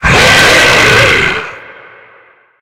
attack_hit_0.ogg